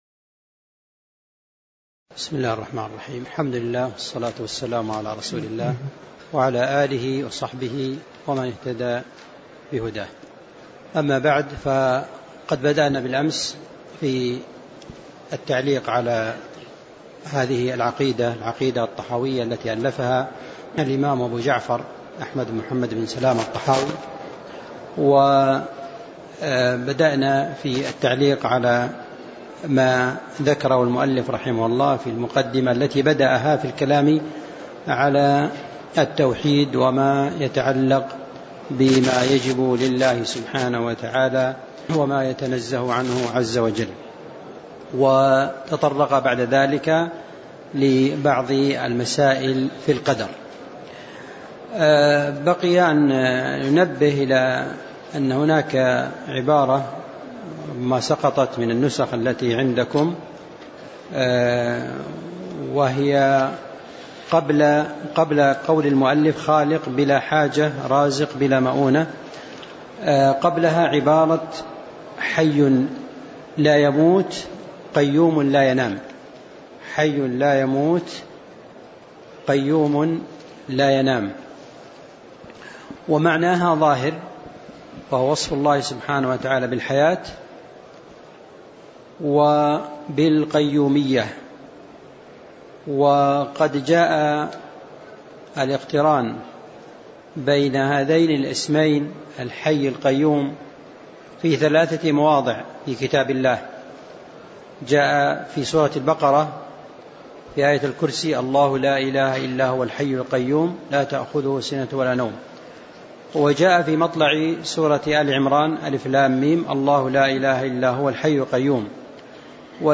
تاريخ النشر ١٠ شوال ١٤٣٩ هـ المكان: المسجد النبوي الشيخ